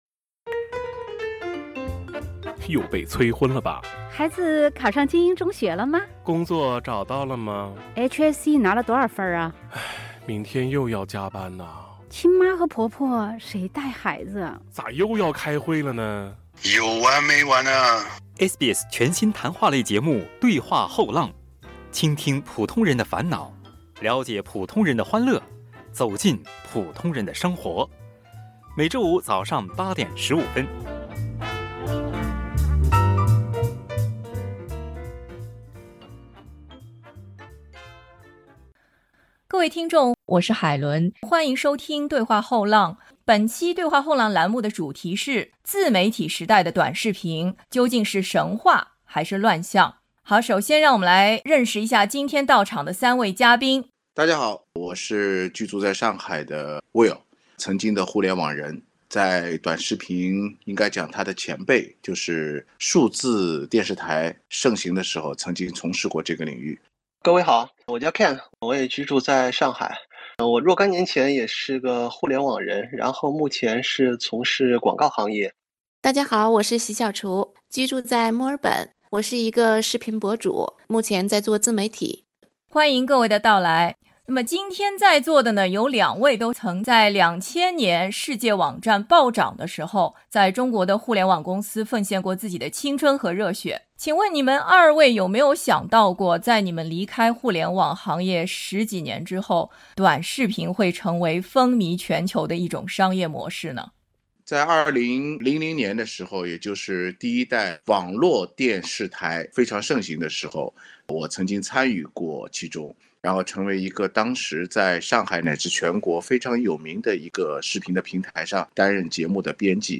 他们三位就目前风靡全球的短视频所引发的各种问题畅所欲言。